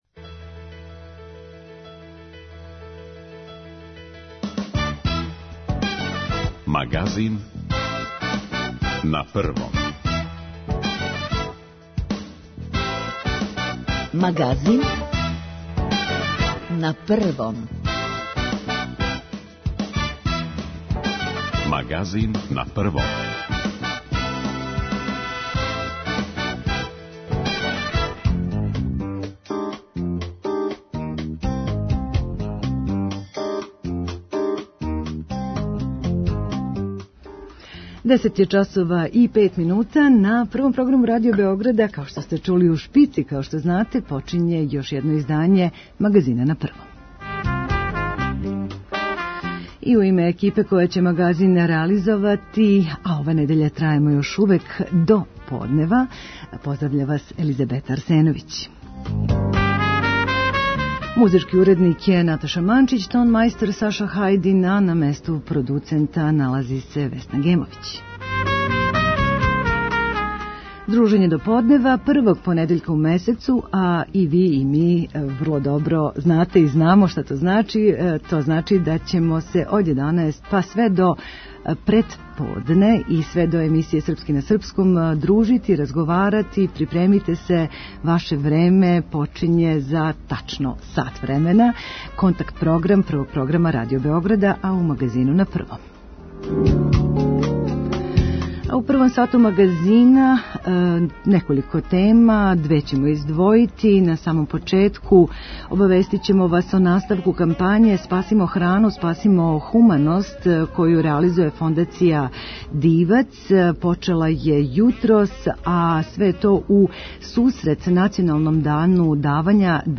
Пред нама и вама је девети контакт-програм Радио Београда 1 првог понедељка у месецу - традиција непрекинута више од једне деценије. И овога пута, од 11 до 12 часова, очекујемо ваше позиве - мишљења, сугестије и примедбе о нашем програму.
Ви говорите, ми слушамо - контакт-програм са слушаоцима Радио Београда 1